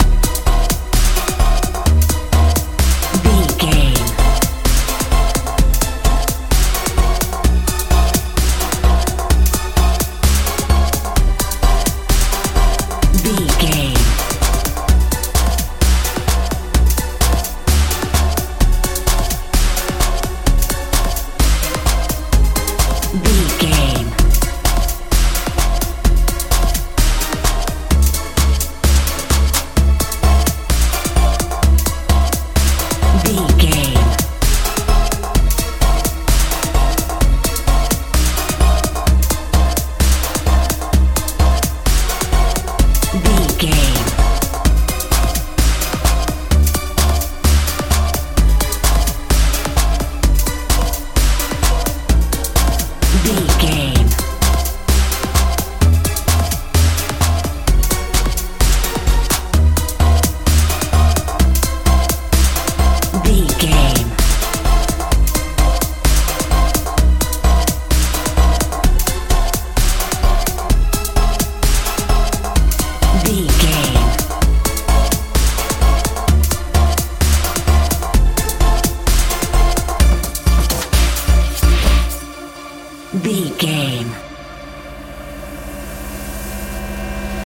euro dance
Ionian/Major
drums
bass guitar
synthesiser
futuristic
dreamy
drum machine